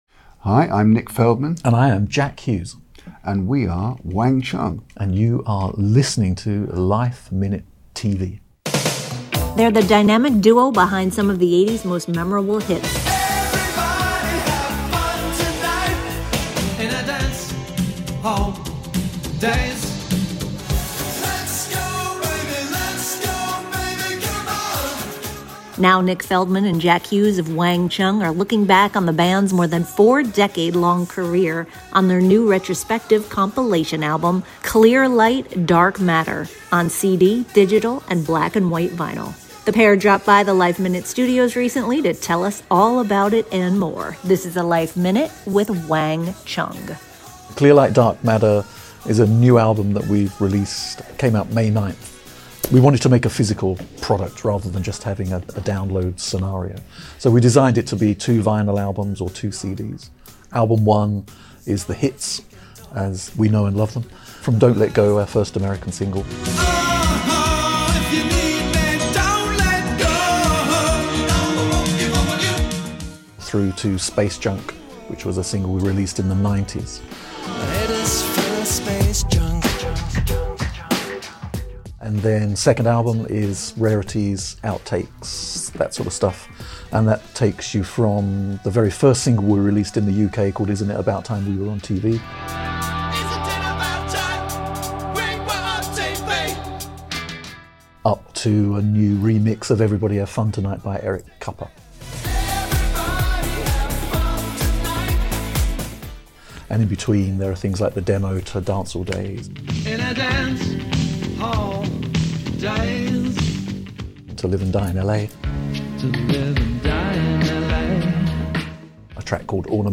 The pair dropped by the LifeMinute studios to tell us all about it, as well as joining Rick Springfield for the 2025 edition of his “I Want My ‘80s Tour.”